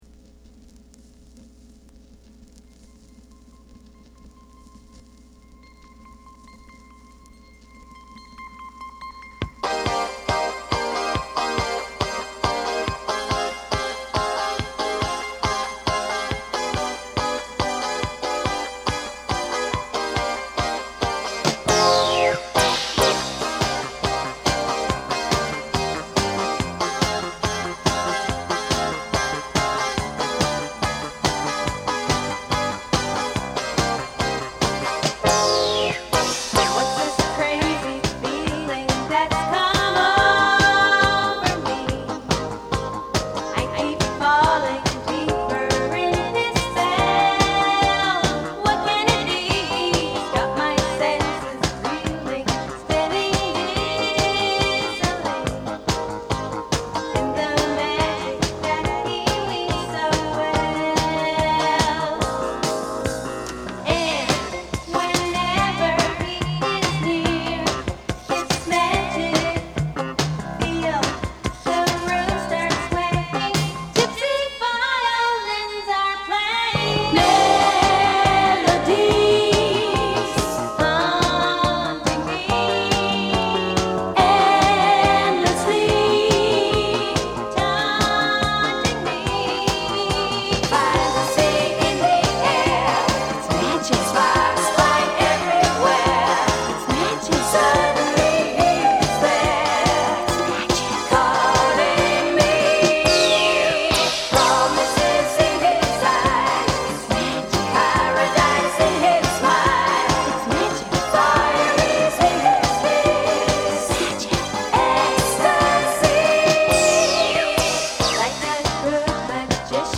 Genre: Jazz Fusion / City Pop
ボーカル入り楽曲を複数収録したポップ色の強い一枚。
ギターのクリーントーンとボーカルが柔らかく絡み合う、明るく開放的な冒頭曲。